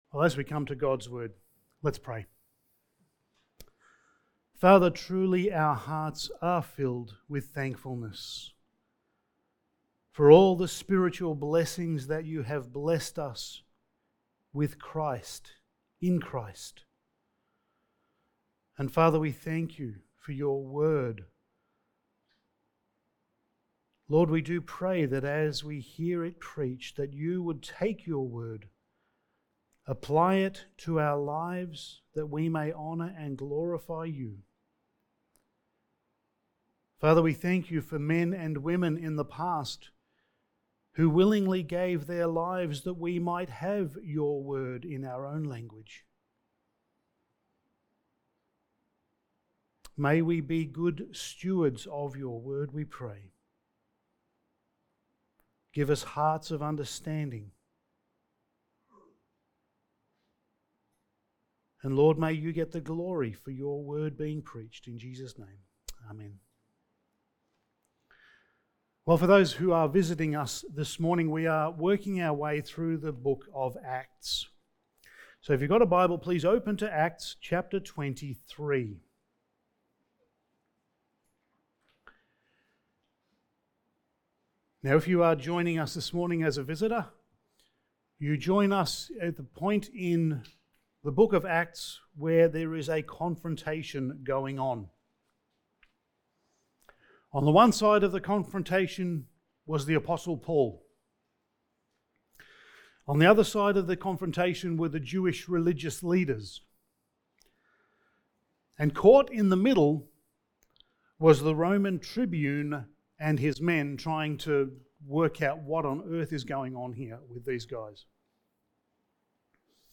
Passage: Acts 23:12-35 Service Type: Sunday Morning